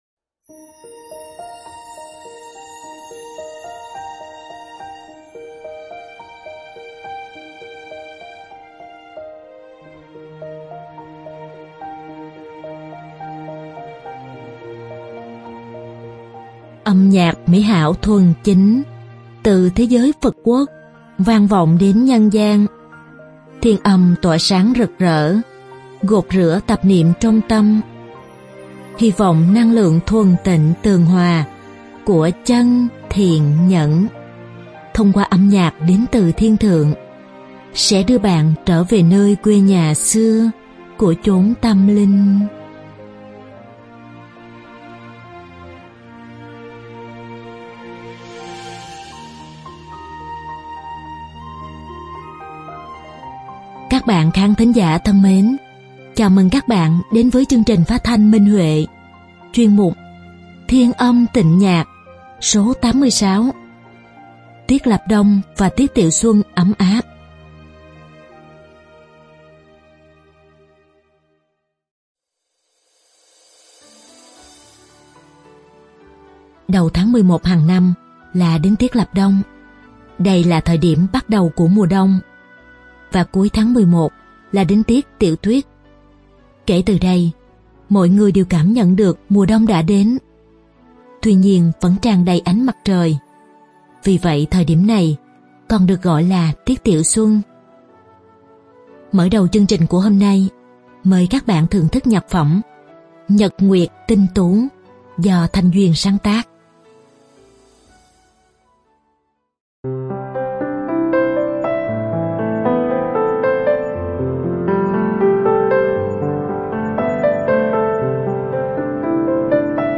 Đơn ca nam
Bài hát thiếu nhi